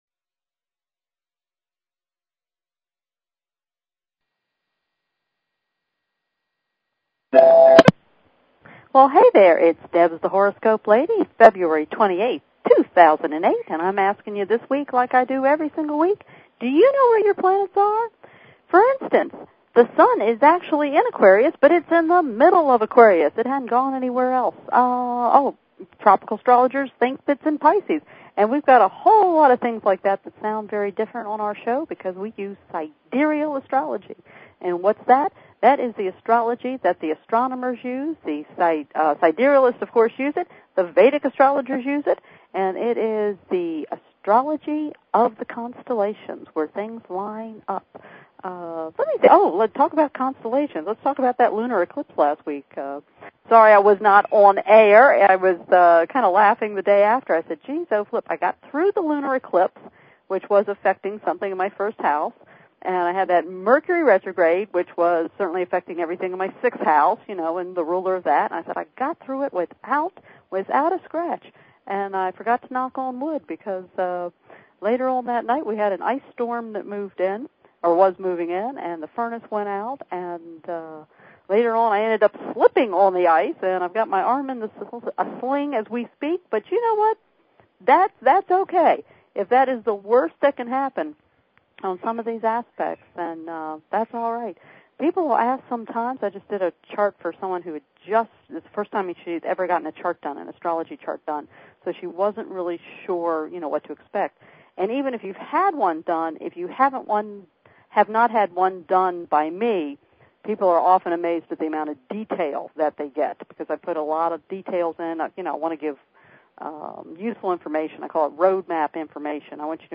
Talk Show Episode
You can e-mail and hear your answers on air.